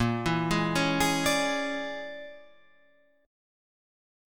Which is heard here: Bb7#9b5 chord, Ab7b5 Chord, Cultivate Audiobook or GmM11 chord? Bb7#9b5 chord